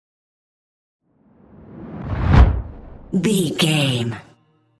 Dramatic whoosh deep trailer
Sound Effects
dark
intense
whoosh